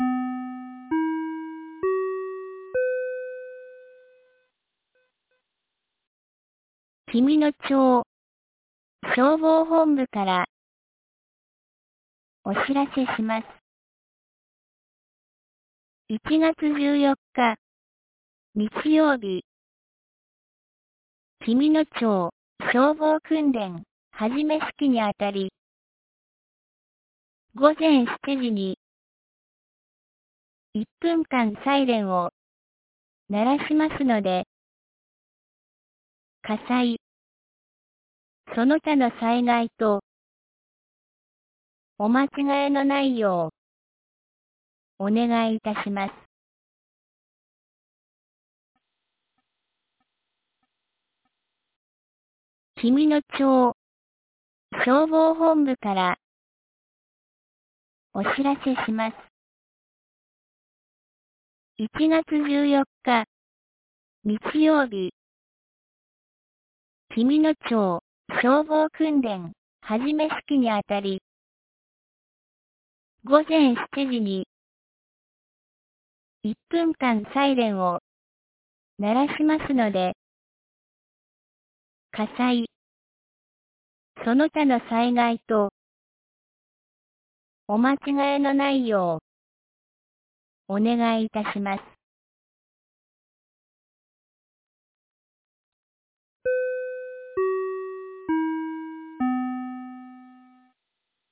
2024年01月13日 12時31分に、紀美野町より全地区へ放送がありました。
放送音声